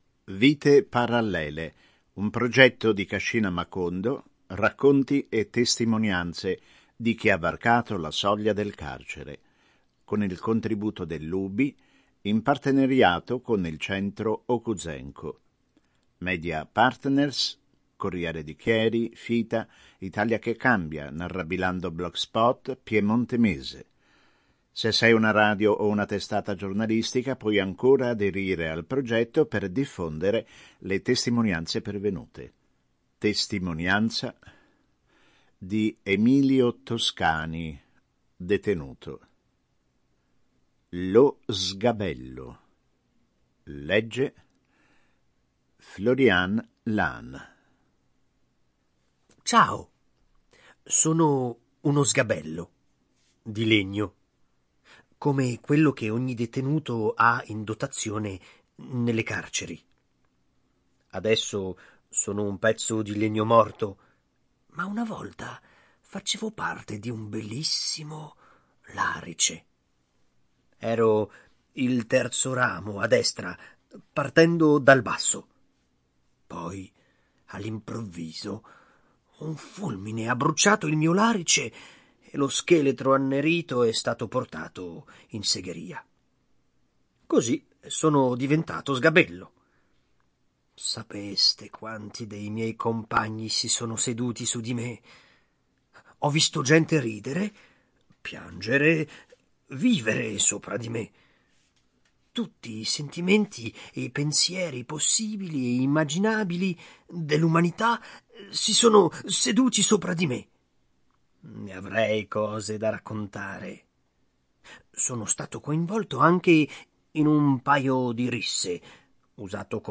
il file audio, con testo registrato, in formato mp3.